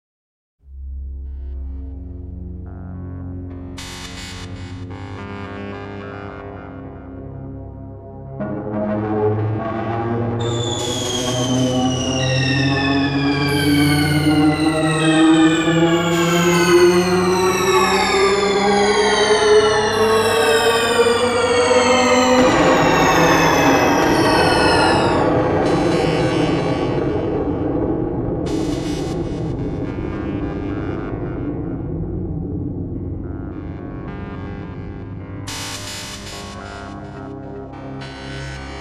Real Techno-Pop.